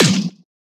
mediumFall.ogg